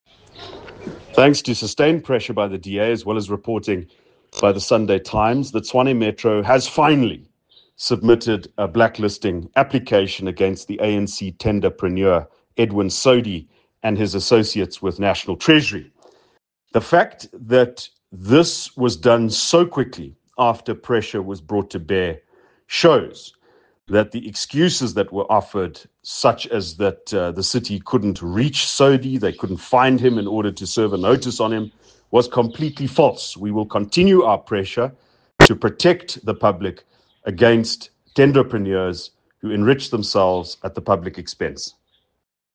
English and Afrikaans soundbites by Ald Cilliers Brink